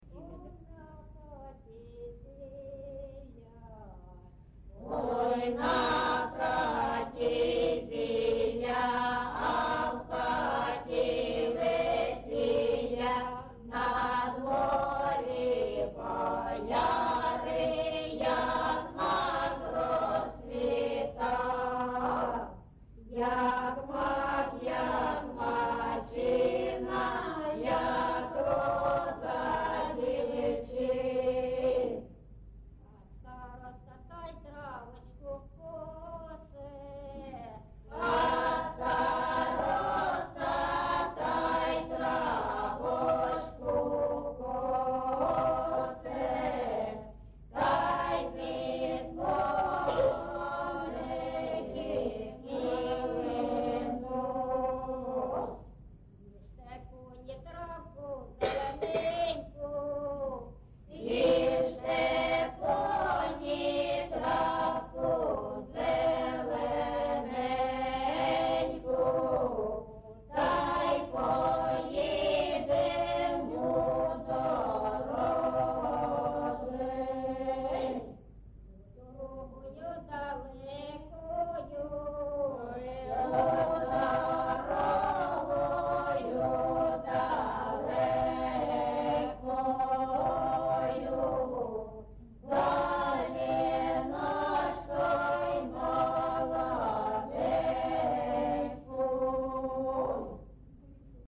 ЖанрВесільні
Місце записус. Маринівка, Шахтарський (Горлівський) район, Донецька обл., Україна, Слобожанщина